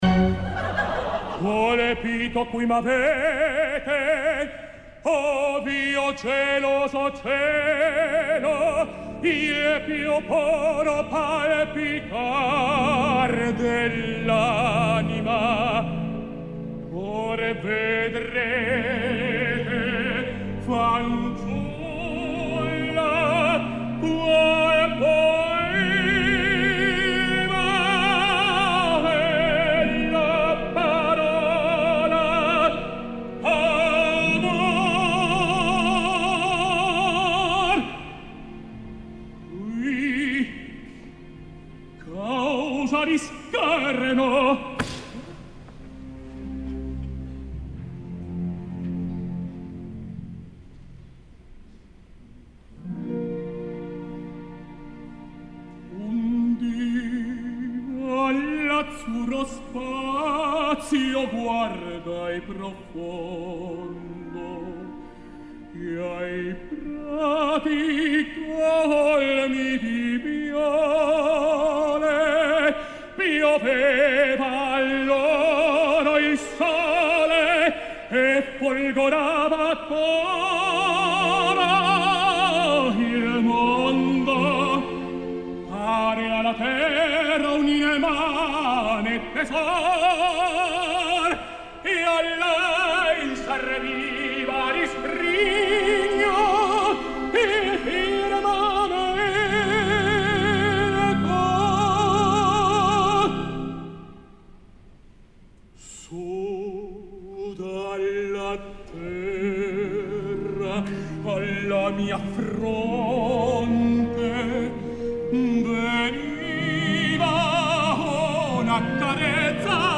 Marcelo Álvarez (Andrea Chénier) al Teatro Real
Us deixo l’Improvvisso del dia 25, per si algú no va tenir la paciència d’escoltar tot aquell decebedor primer acte.
En quant al Improvviso que ens has deixat, jo el trobo molt irregular.